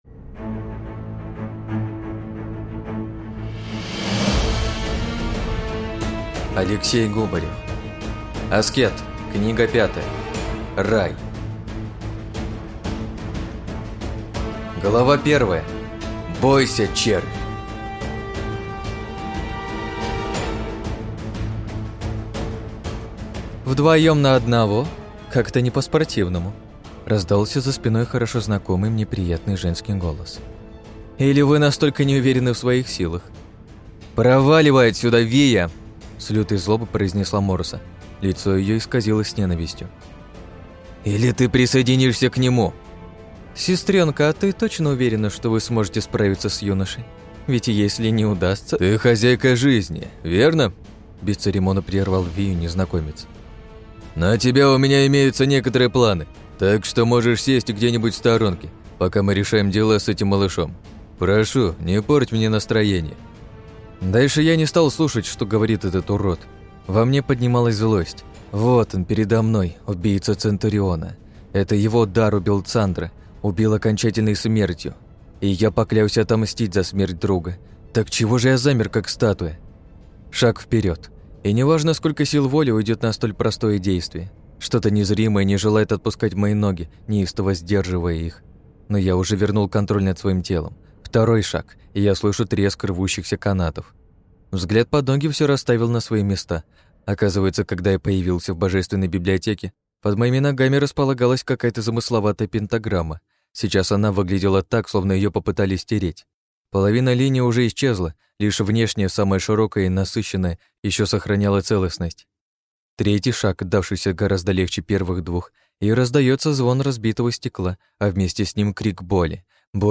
Аудиокнига Аскет. Рай | Библиотека аудиокниг
Прослушать и бесплатно скачать фрагмент аудиокниги